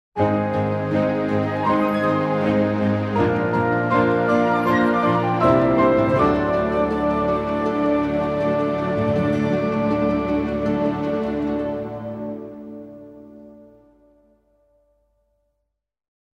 ethnique - profondeurs - epique - flute de pan - perou